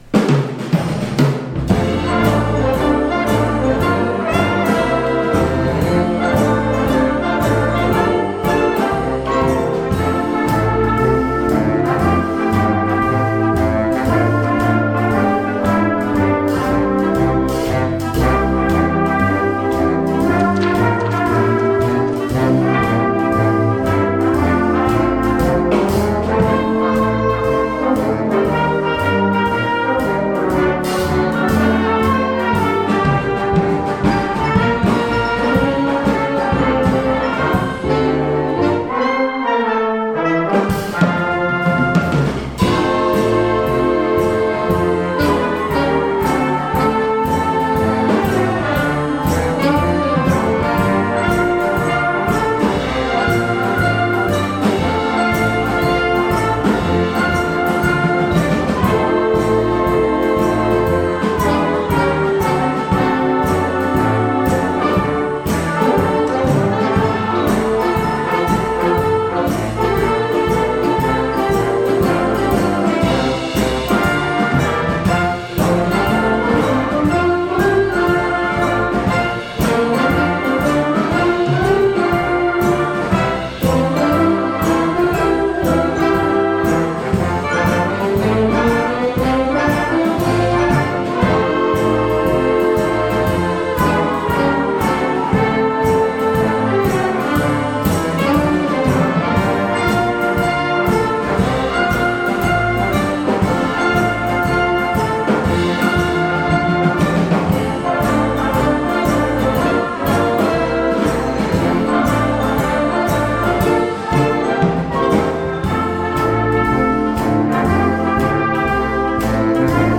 Audio – Sackville Community Band Society
Portia White Concert November 22nd 2019